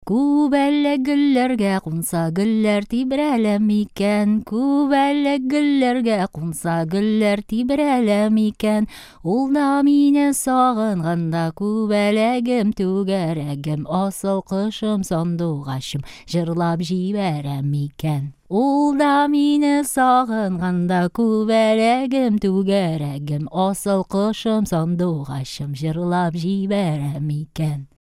Песня о бабочке и о любви в исполнении Алсу Курмашевой